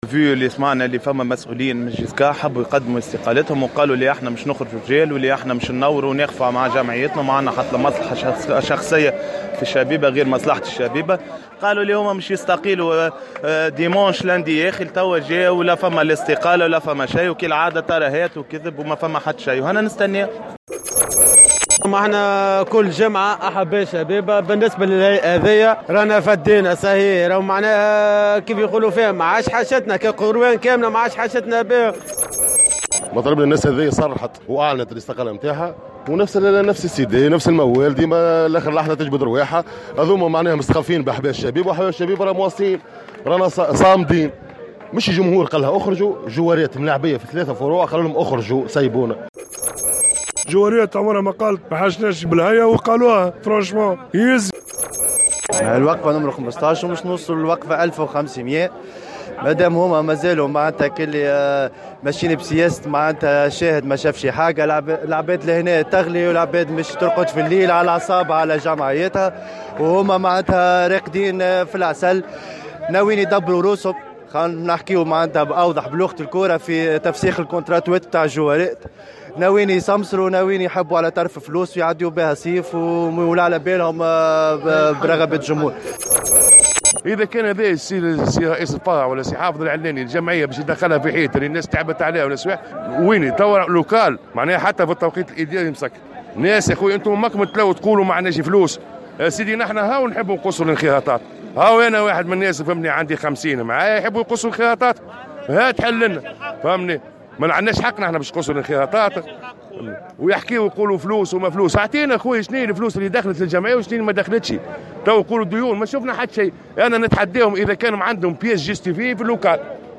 نظمت مجموعة من أحباء الشبيبة القيروانية عشية اليوم الثلاثاء 15 ماي 2018 وقفة إحتجاجية أمام مقر النادي لمطالبة الهيئة المديرة للشبيبة بتقديم استقالتها الفورية مع تحديد موعد للجلسة العامة الانتخابية و فتح باب الترشح في أقرب الاجال.